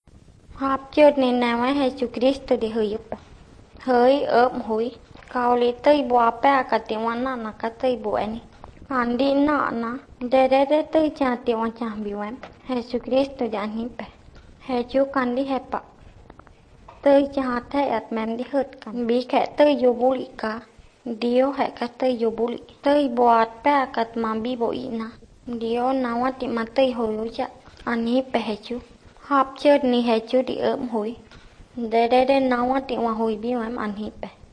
29 February 2016 at 11:08 am With the palatals, high unrounded vowels, breathy vowels, and glottalized consonants, I wanted to aim for Mainland Southeast Asia, but all the clear Spanish borrowing (e.g. “Jesucristo”), I will go for an indigenous language of Latin America.